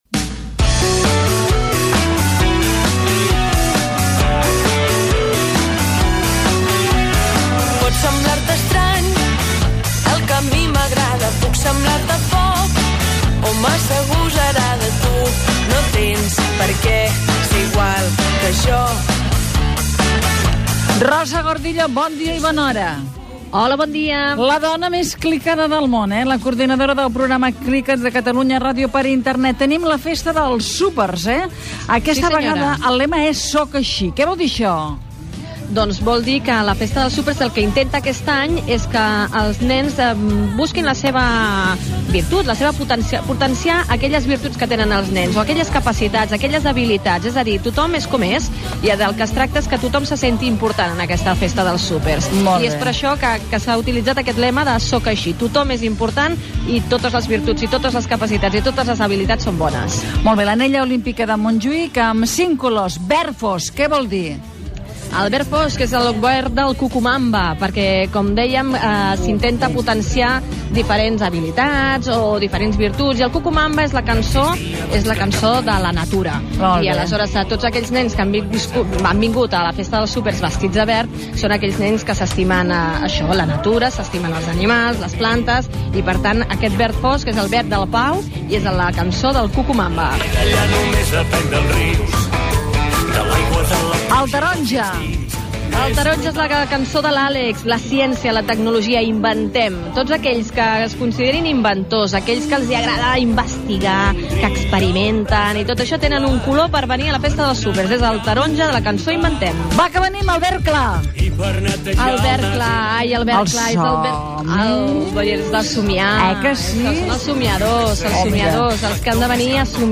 Connexió amb la Festa dels Súpers de TV3 Televisió de Catalunya a l'Estadi de Montjuïc de Barcelona
Entreteniment